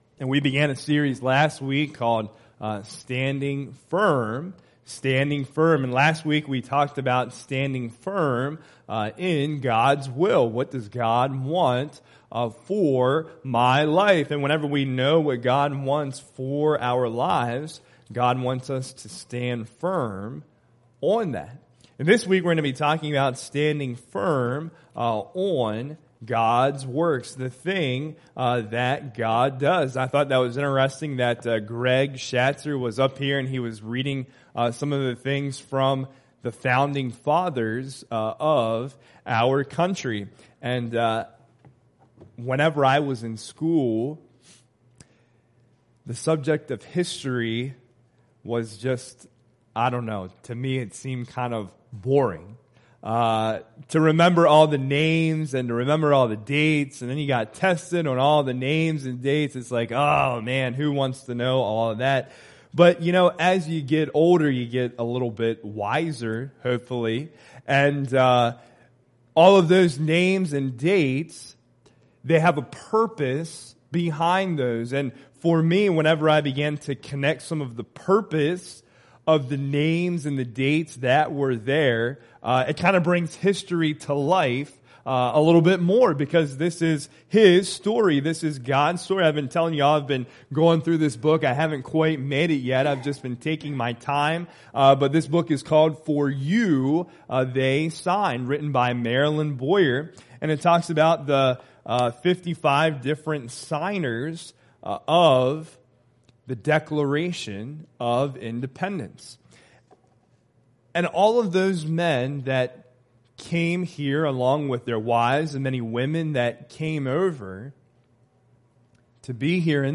Proverbs 3:1-5 Service Type: Sunday Morning Worship Service Check out the amazing things God has done.